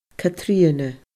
Catrìona /kaˈtriənə/